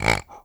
ANIMAL_Pig_Grunt_mono.wav